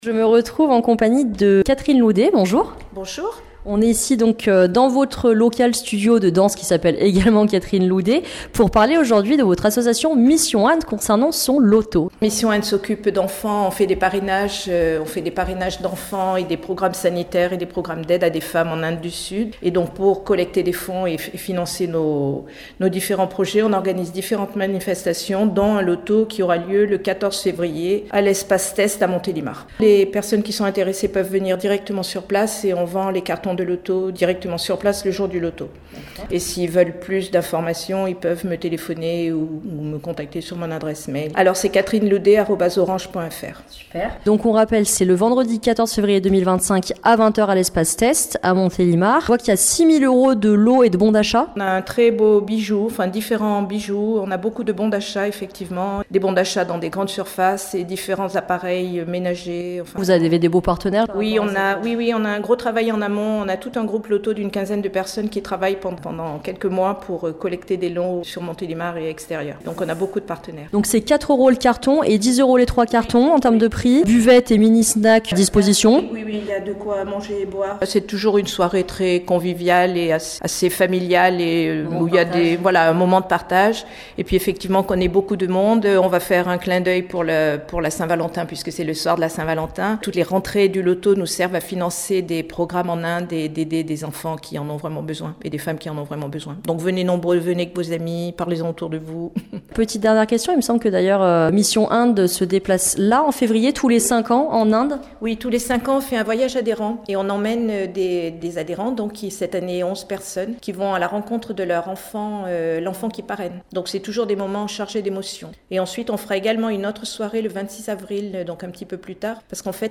A la radio, on entend parler de Mission Inde. Ici, avec Soleil FM Montélimar, on a pu entendre parler, durant le mois de février 2025, du loto à venir, mais également de ce que faisait Mission Inde.
Interview